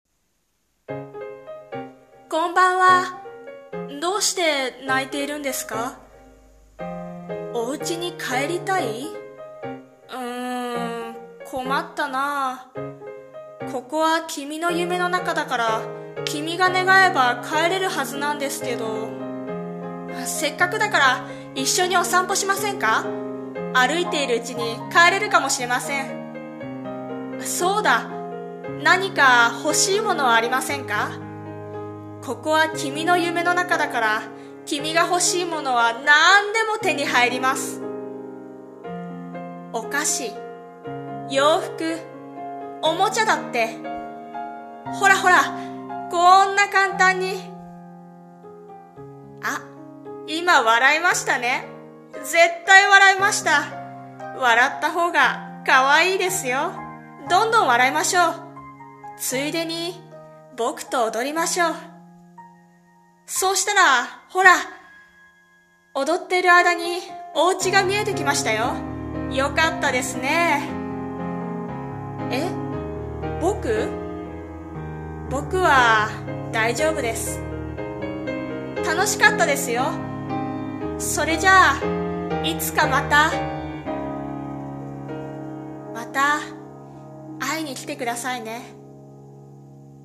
】【声劇】夢の中からこんばんは。